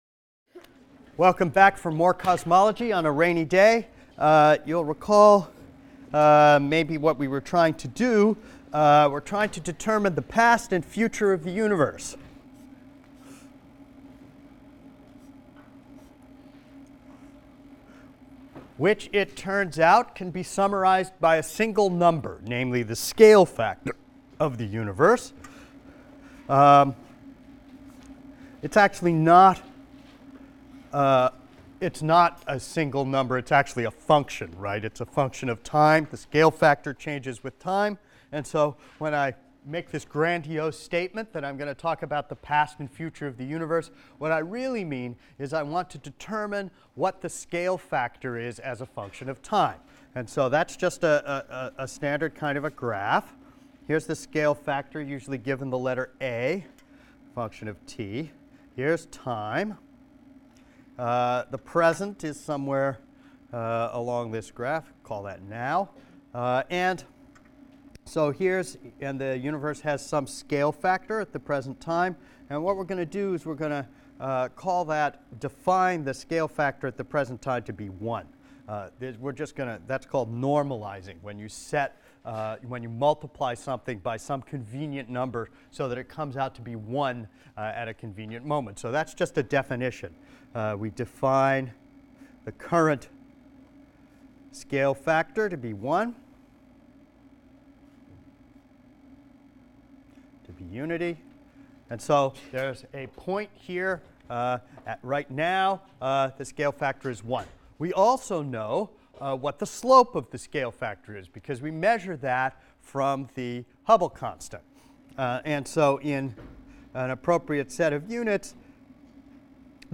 ASTR 160 - Lecture 20 - Dark Matter | Open Yale Courses